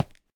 step5.ogg